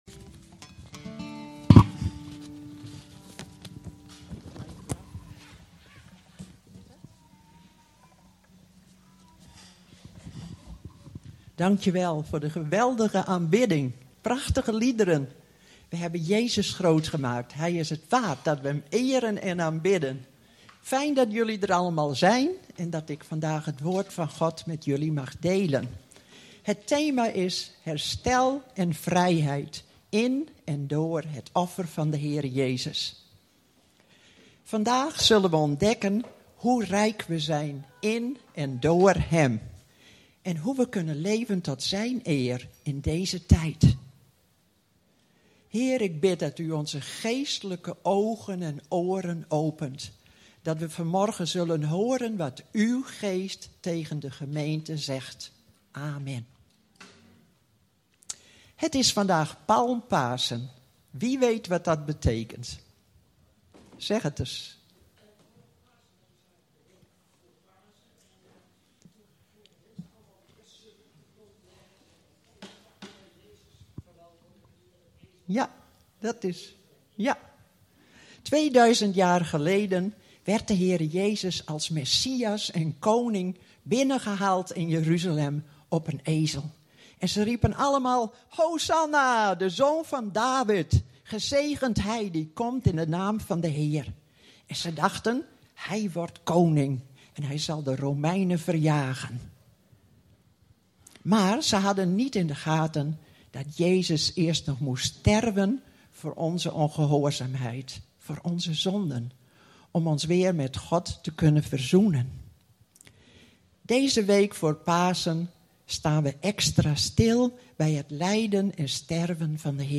In deze podcast staan alle opgenomen toespraken van Leef! Zutphen vanaf 3 februari 2008 t/m nu.